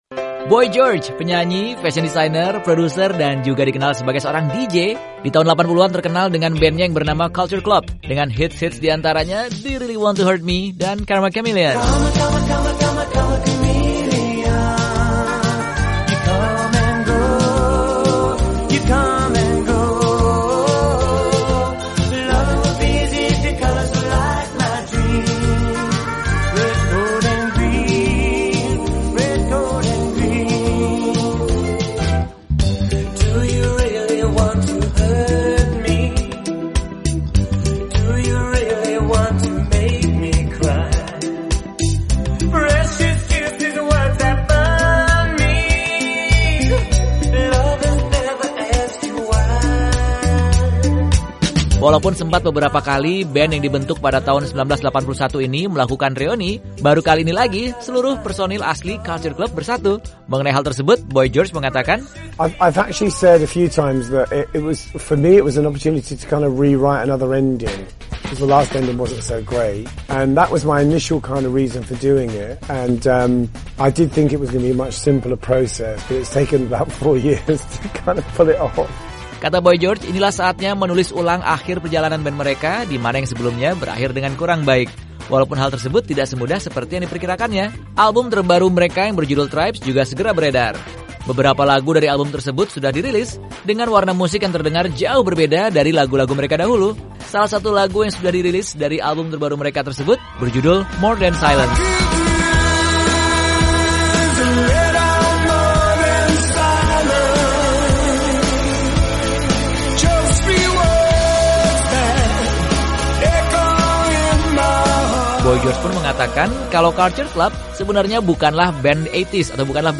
Simak obrolan bersama Boy George, seputar bandnya yang hits di tahun 80-an, Culture Club, yang sekarang sedang melakukan tour reuni dan segera meluncurkan album terbaru mereka.